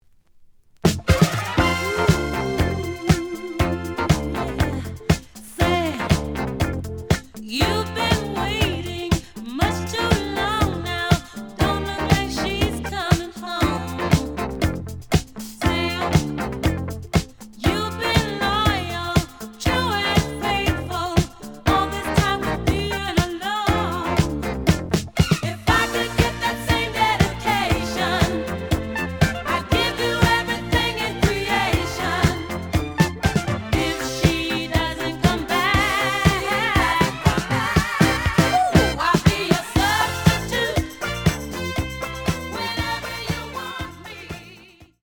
The audio sample is recorded from the actual item.
●Format: 7 inch
●Genre: Disco
Slight edge warp.